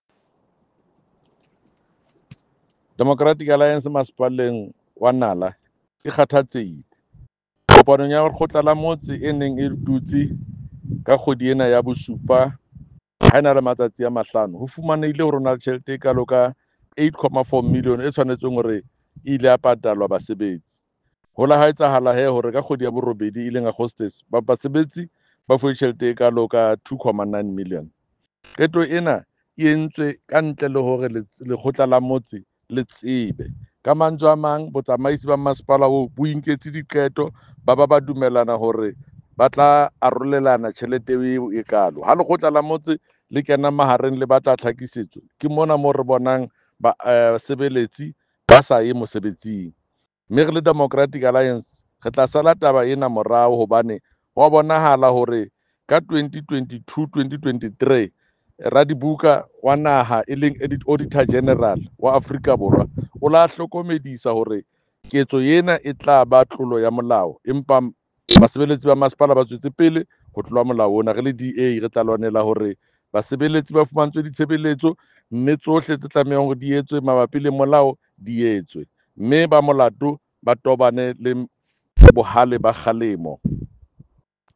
Sesotho by Jafta Mokoena MPL.
Sotho-voice-Jafta-2.mp3